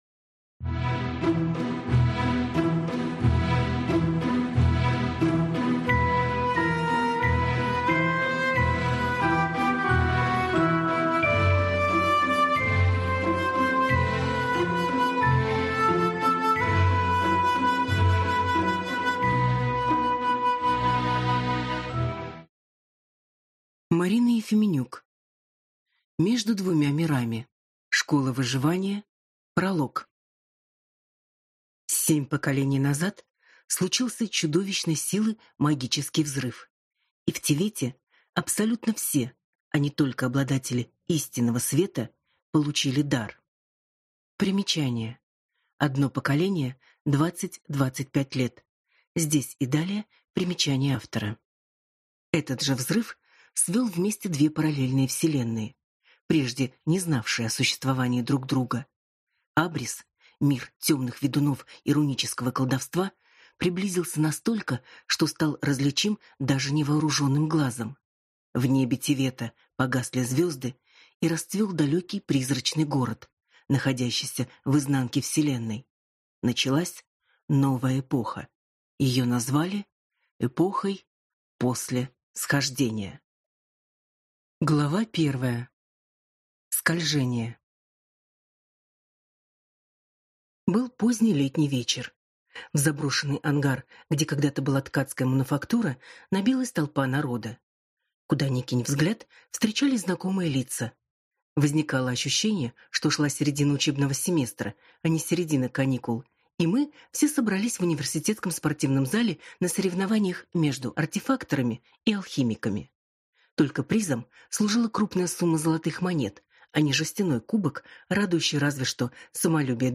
Аудиокнига Между двумя мирами. Школа выживания | Библиотека аудиокниг